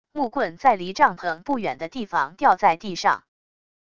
木棍在离帐篷不远的地方掉在地上wav音频